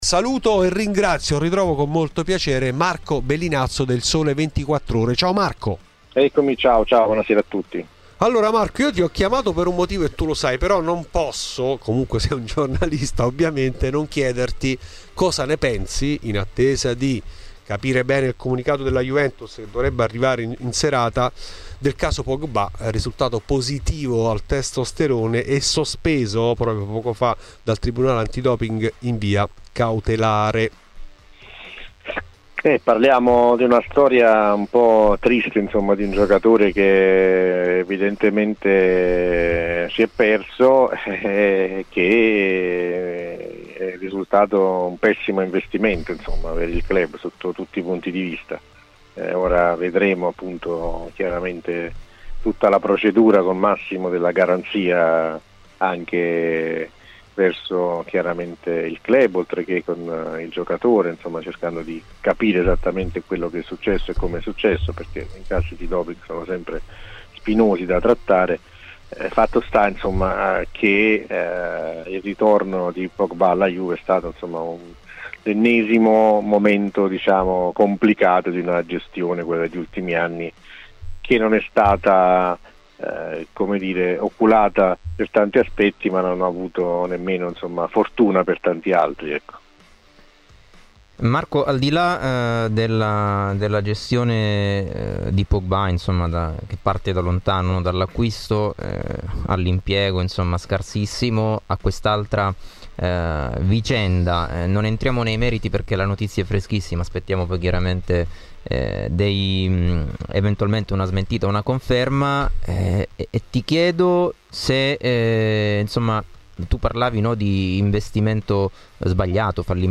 Nel podcast l'intervento integrale